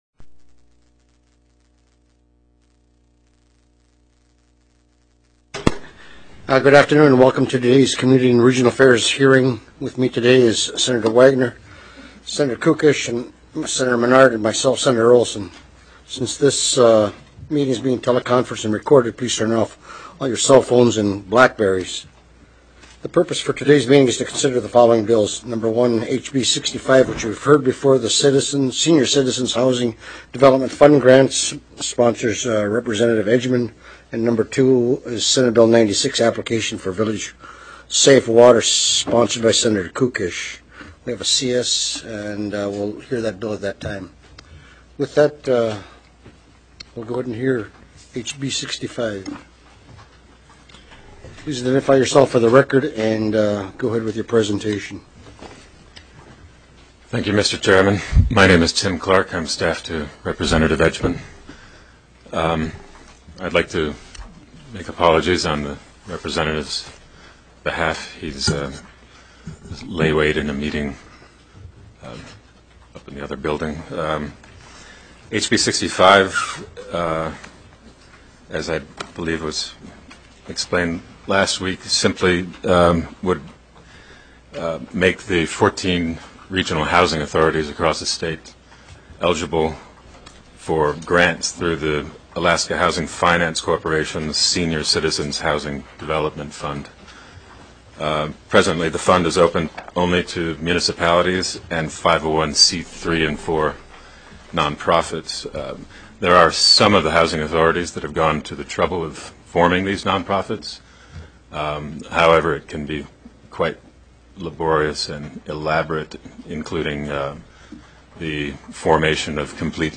SB 96 APPLICATION OF VILLAGE SAFE WATER ACT TELECONFERENCED